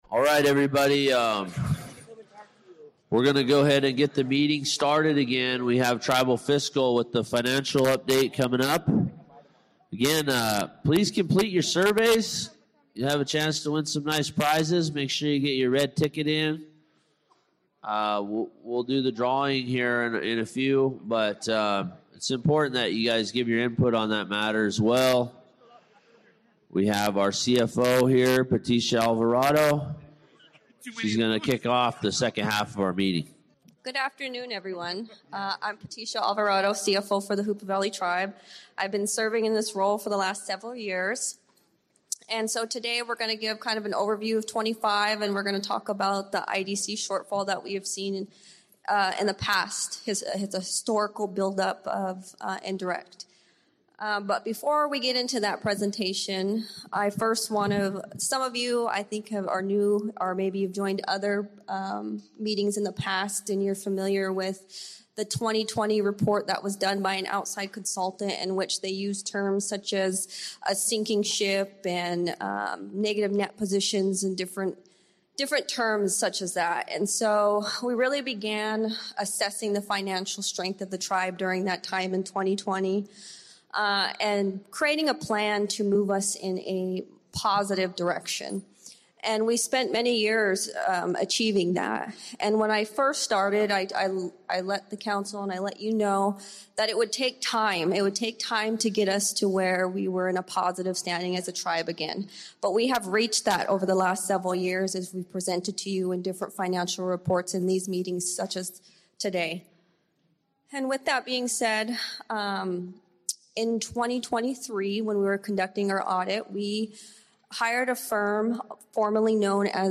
This is the 3rd hour of the six-hour recording of the Hoopa Valley Tribal General Meeting of 2-21-26.